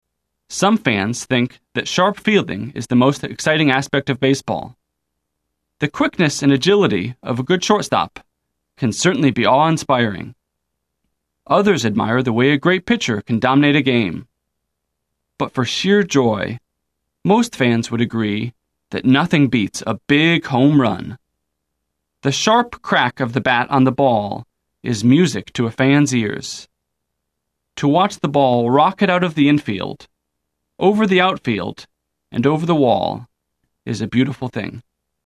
聽力大考驗：來聽老美怎麼說？